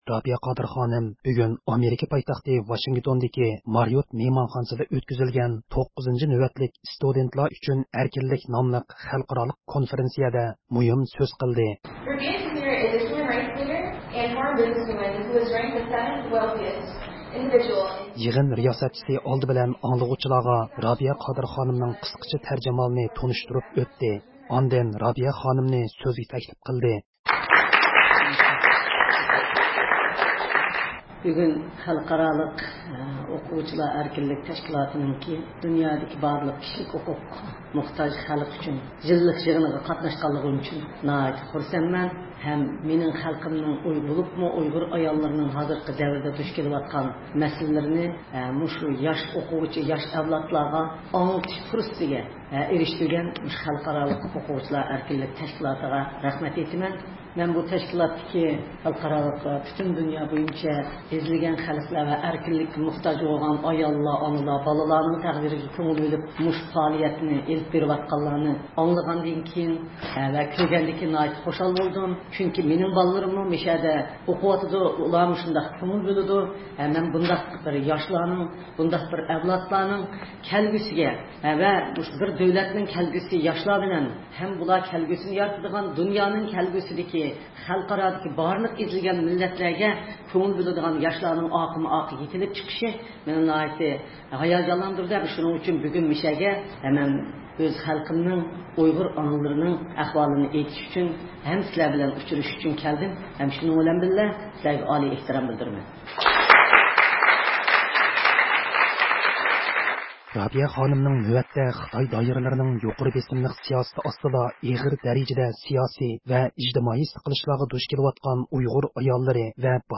رابىيە قادىر خانىم ۋاشىنگتوندا ئۆتكۈزۈلگەن «ستۇدېنتلار ئۈچۈن ئەركىنلىك» ناملىق خەلقئارا كونفېرېنسىيەدە مۇھىم سۆز قىلدى – ئۇيغۇر مىللى ھەركىتى
27-فېۋرال، يەنى شەنبە كۈنى ئۇيغۇر مىللىي ھەرىكىتىنىڭ رەھبىرى رابىيە قادىر خانىم ئامېرىكا پايتەختى ۋاشىنگتوندىكى مارىئوت مېھمانخانىسىدا ئۆتكۈزۈلگەن 9-نۆۋەتلىك «ستۇدېنتلار ئۈچۈن ئەركىنلىك» ناملىق خەلقئارالىق كونفېرېنسىيەدە مۇھىم سۆز قىلدى.